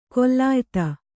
♪ kollāyta